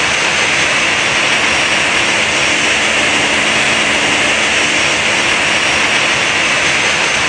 x320_idle.wav